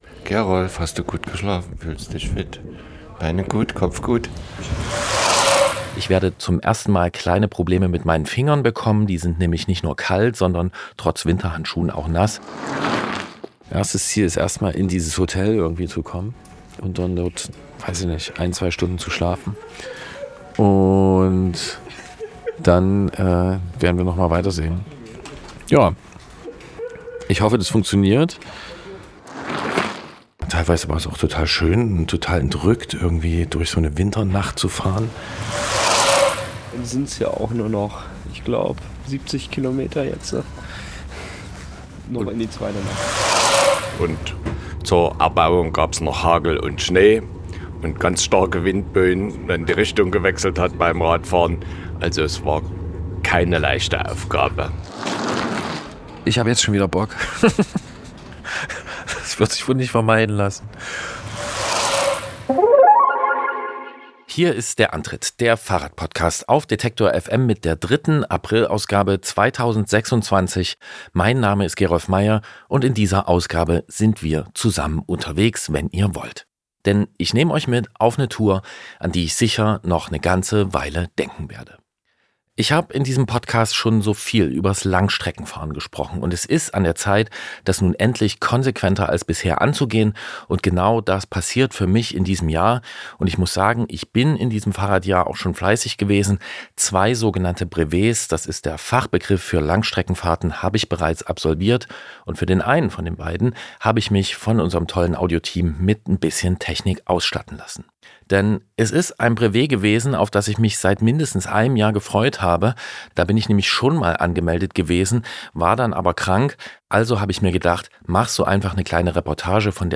Reportage: In einem Stück zum Mont Ventoux